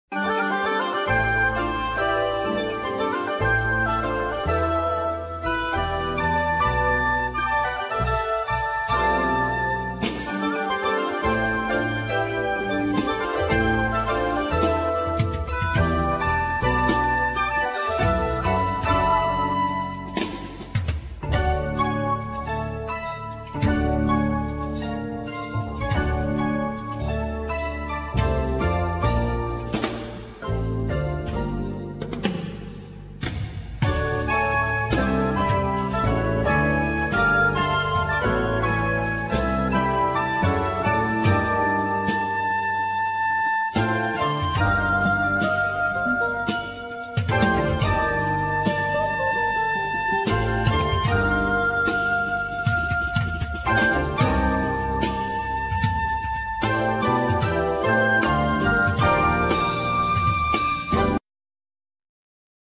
Oboe, Bass clarinet, Soprano & Sopranino Sax, Whistles
Guitars, Pianos, Synthesizers
Bass, Piano
Percussions, Voice